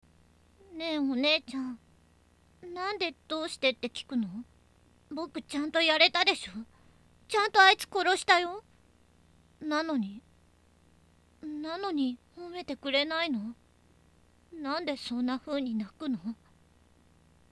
サンプルボイス≪44100Hz/16bit/モノラル≫
〜幼め
声の高さ→高の高